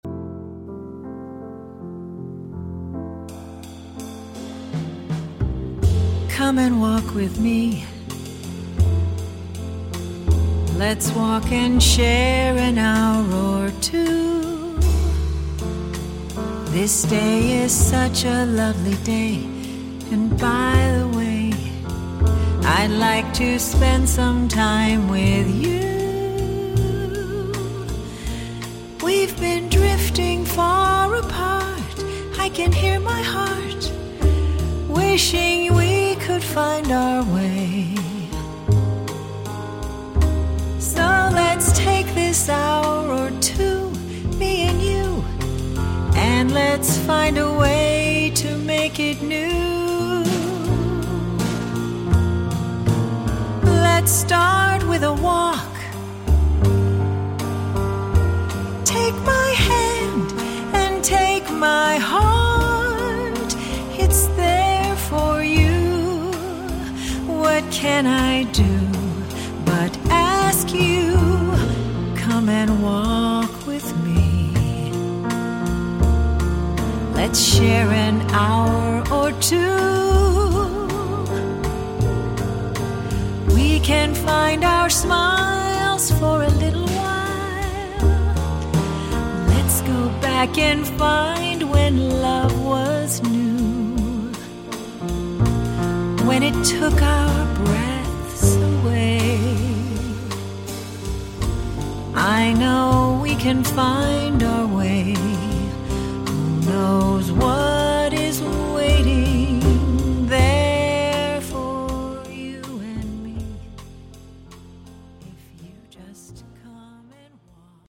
Another album of light jazz, blues, ballads, and bossas.